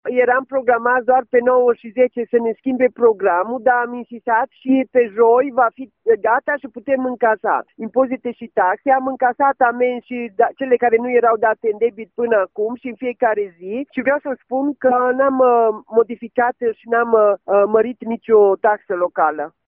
Valoarea impozitelor și taxelor, dar și bonificația rămân neschimbate, spune primarul municipiului, Maria Precup: